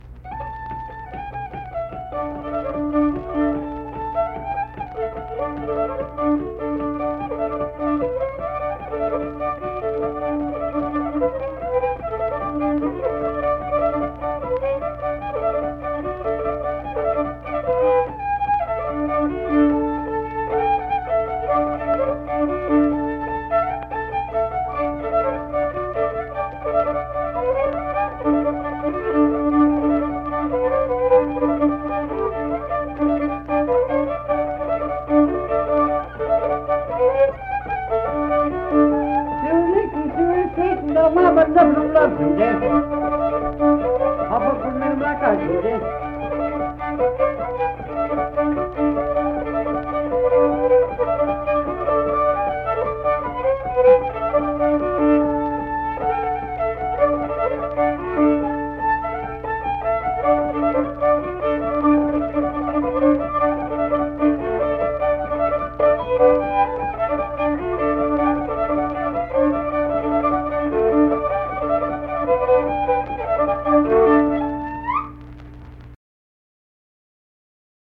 Unaccompanied fiddle music
Instrumental Music
Fiddle
Mingo County (W. Va.), Kirk (W. Va.)